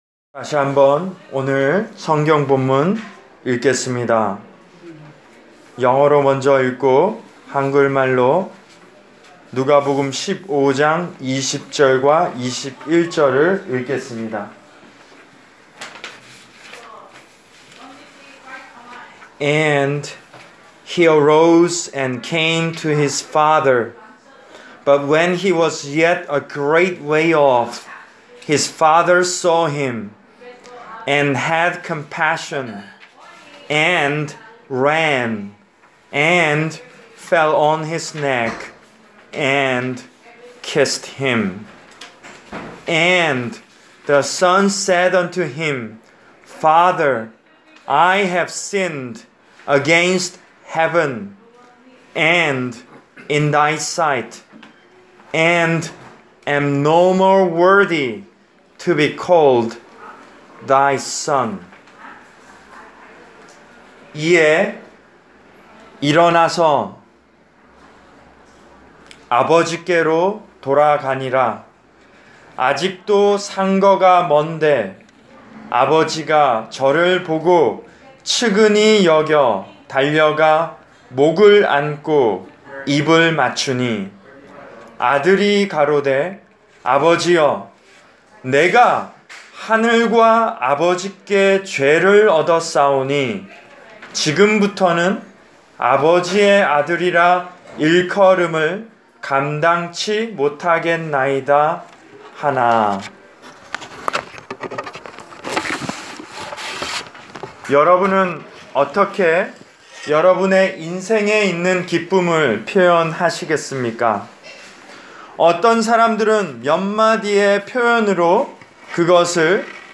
Preached for: Country Arch Care Center, Pittstown, N.J.